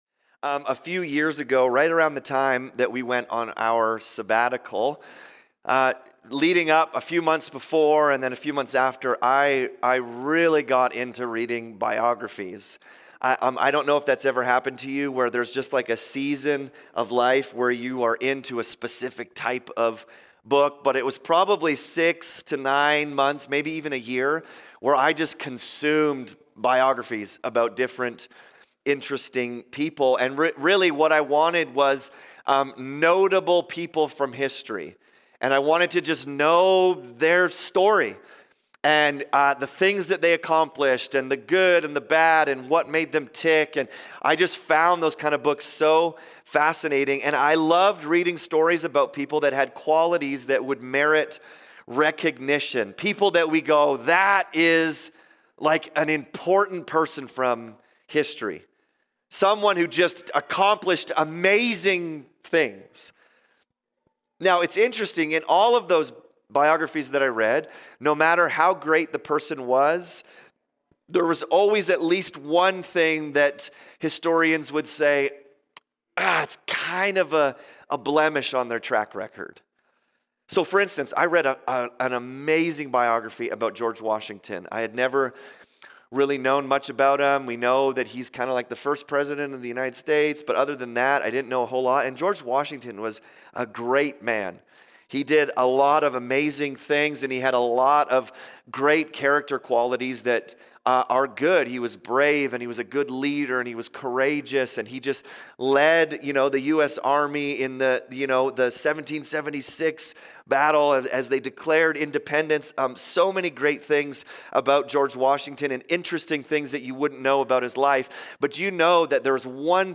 Subscribe via iTunes to our weekly Sermons